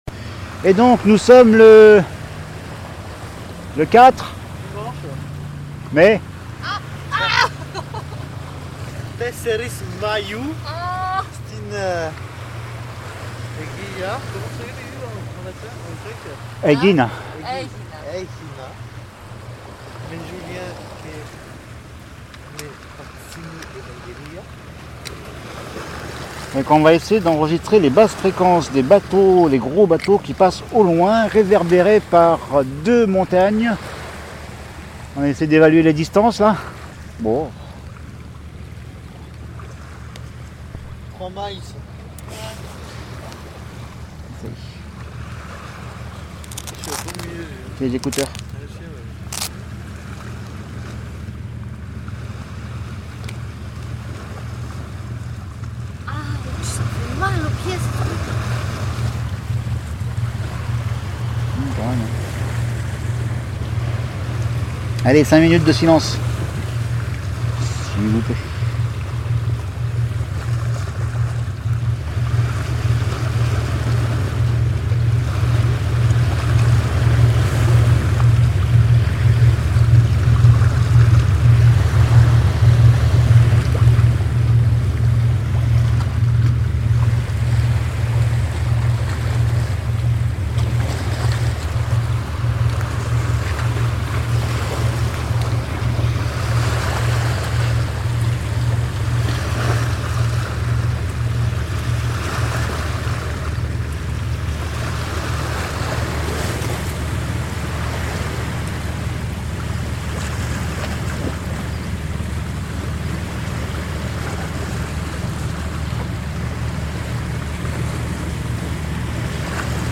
2 - Basses fréquences / sur la plage de Perdika
Surpris par la résonnance ou la réverbération des gros bateaux qui passent, si loin au large, j'ai d'abord cru que les îles montagneuses, en face et sur le côté, nous renvoyaient le son des moteurs qui vibrent.
aTHina_05_AEGINA_BASSES.MP3